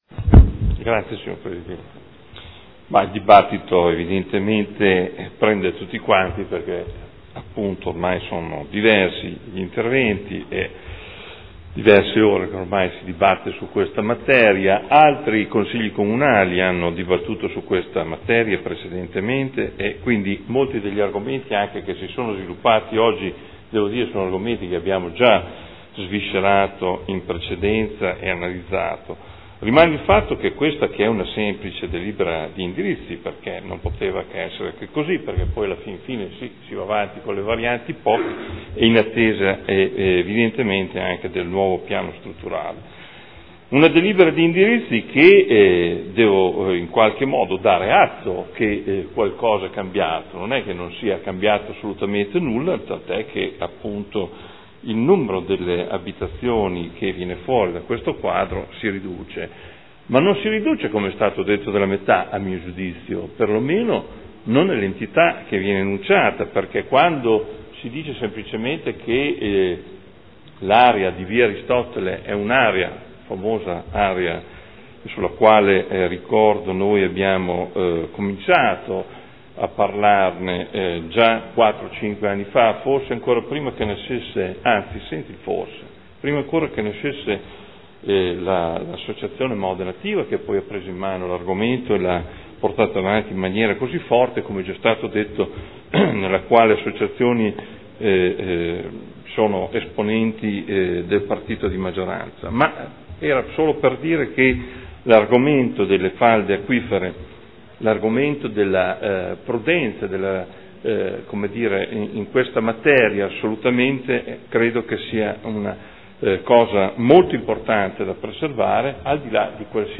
Seduta del 15/07/2013 Dibattito. Perequazione nelle Zone F – Revisione dell’art. 16.7 bis POC